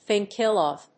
thìnk íll of…